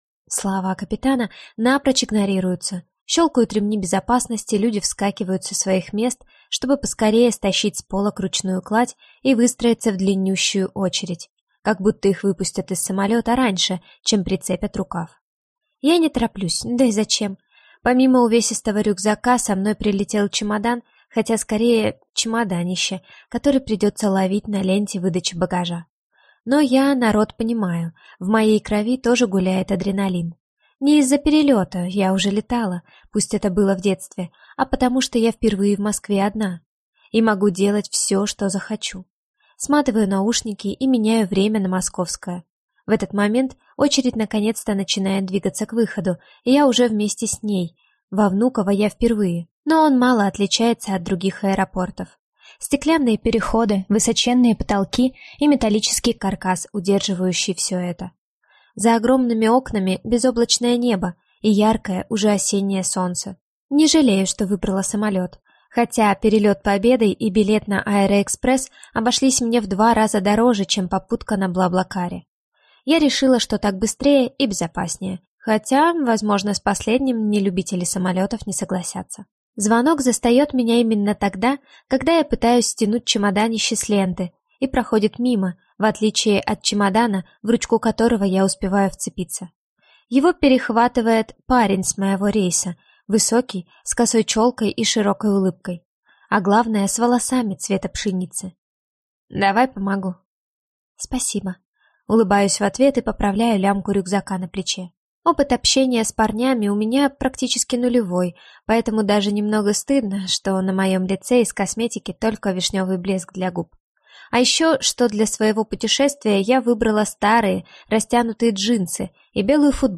Аудиокнига Желание #5 | Библиотека аудиокниг
Прослушать и бесплатно скачать фрагмент аудиокниги